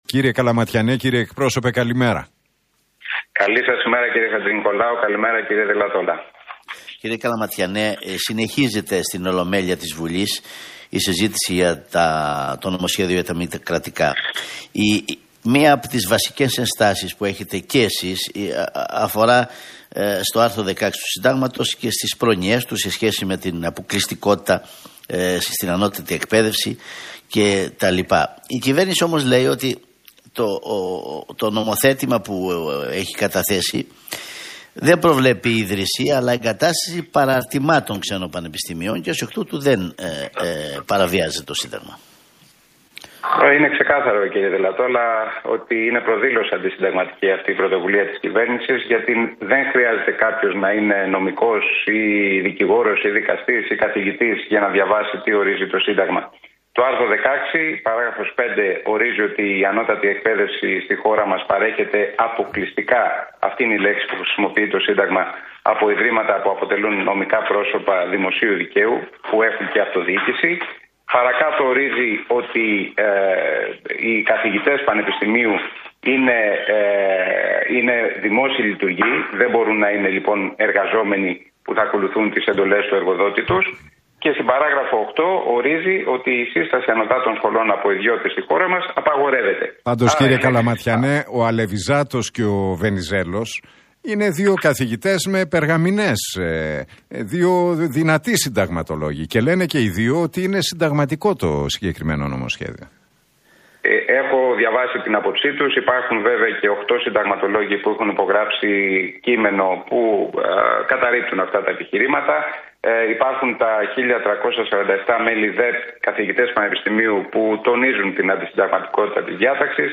Καλαματιανός στον Realfm 97,8: Η κυβέρνηση βάζει το κάρο μπροστά από το άλογο και θέλει ιδιωτικά πανεπιστήμια